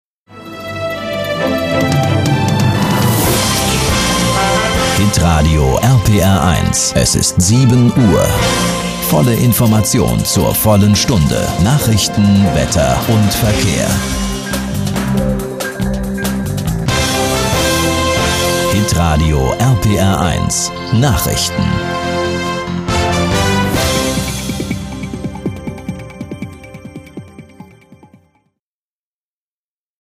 deutscher Synchronsprecher, Off-Stimme, Moderator, Werbesprecher, Hörspiel, Trickstimme, Dialekte
Sprechprobe: Industrie (Muttersprache):
german voice over artist